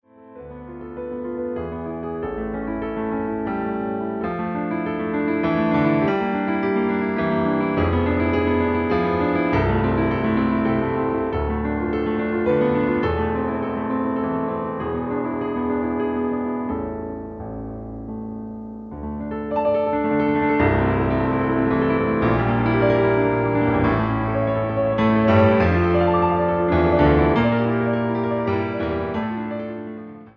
A Ballet Class CD